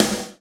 SNR HARD 05R.wav